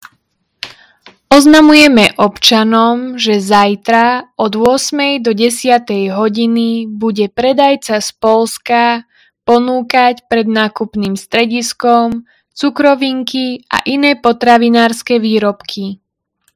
Hlásenie obecného rozhlasu – Predaj cukroviniek 15.07.2025